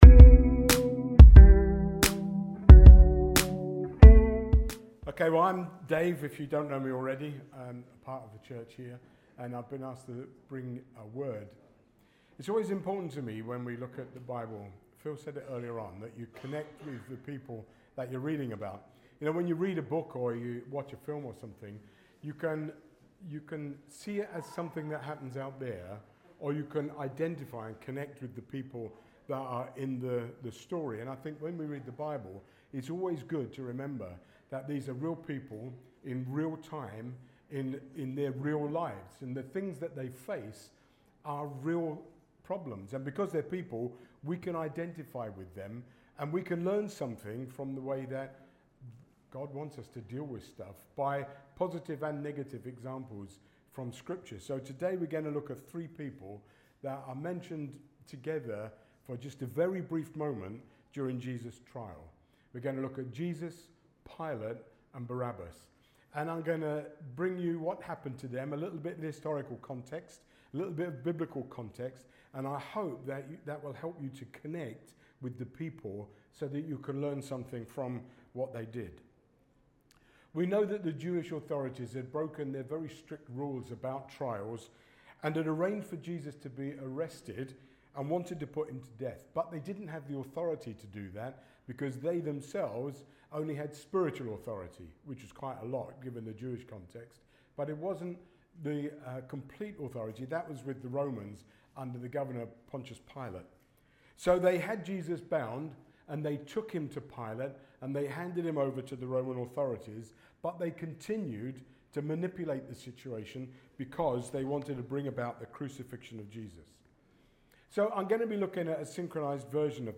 Rediscover Church Newton Abbot | Sunday Messages